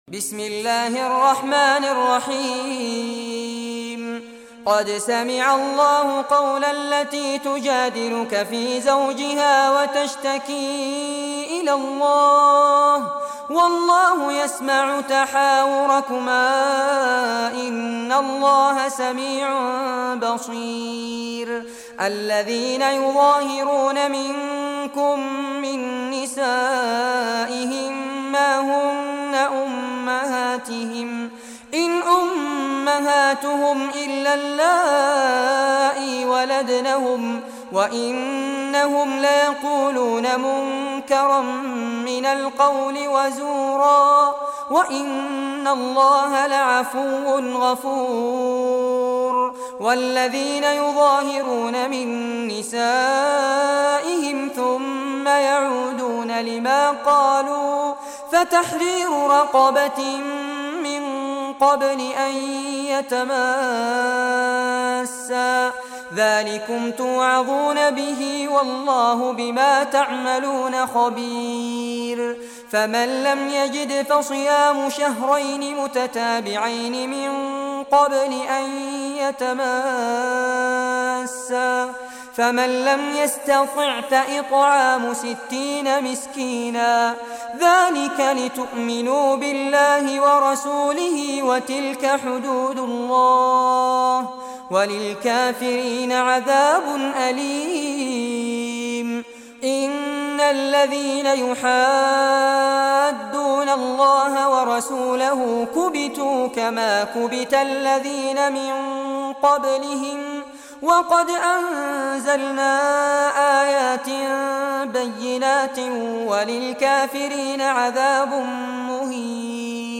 Surah Mujadila, listen or play online mp3 tilawat / recitation in Arabic in the beautiful voice of Sheikh Fares Abbad.